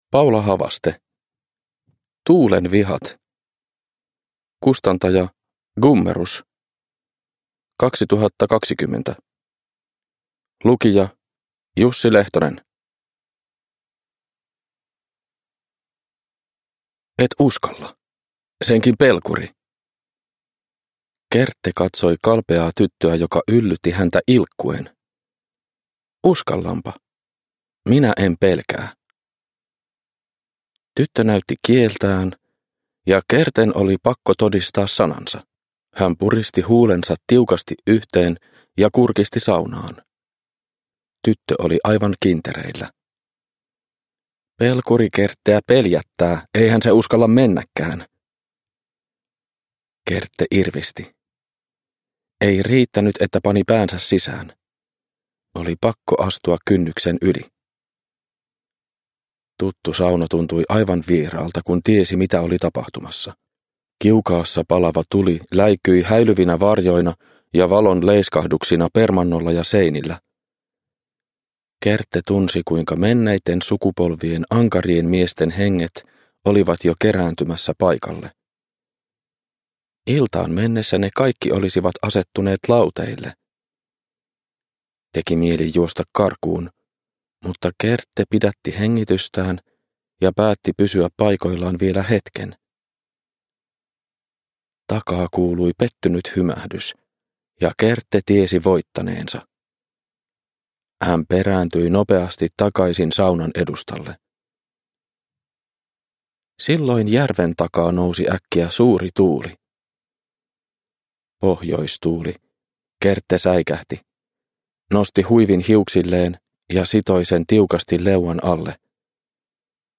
Tuulen vihat – Ljudbok – Laddas ner